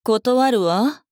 大人女性│女魔導師│リアクションボイス